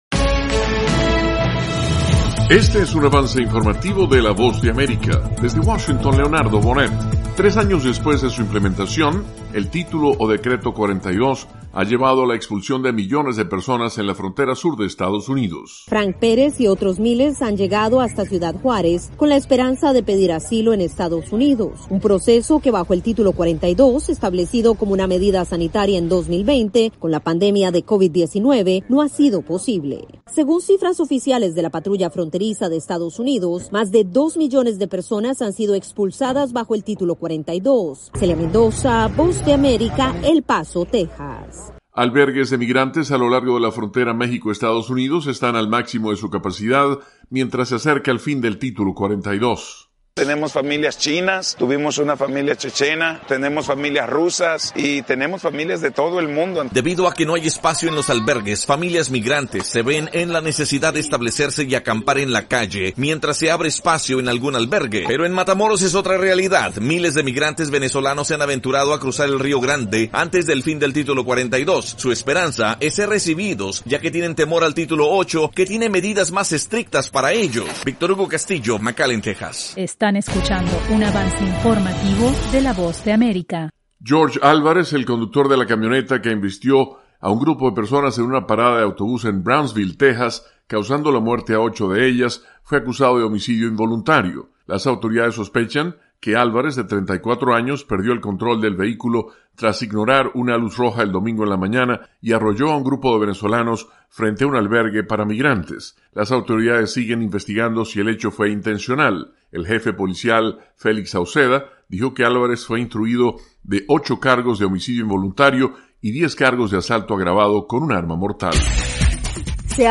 El siguiente es un avance informativo presentado por la Voz de América, desde Washington, con